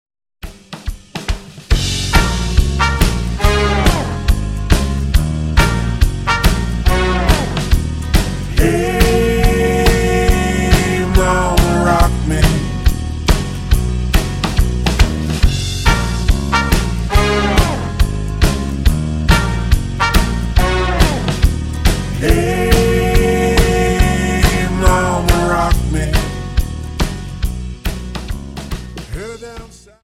Dance: Jive 42